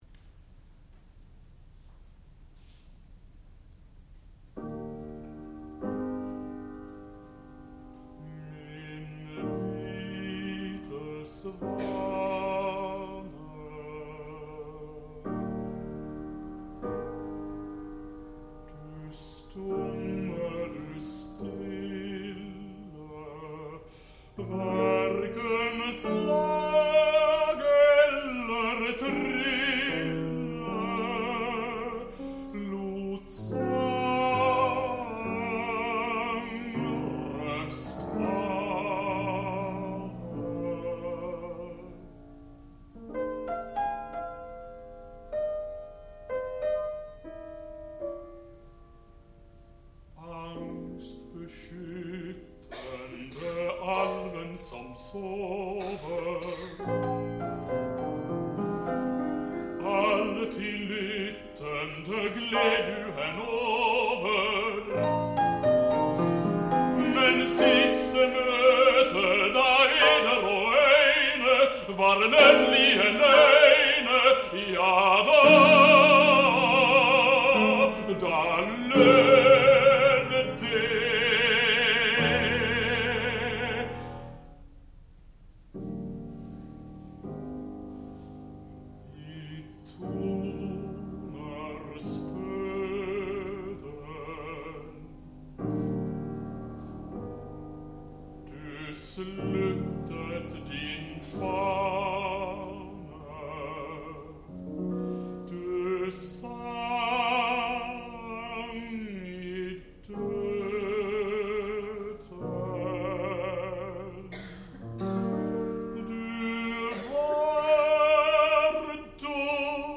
classical music
baritone
piano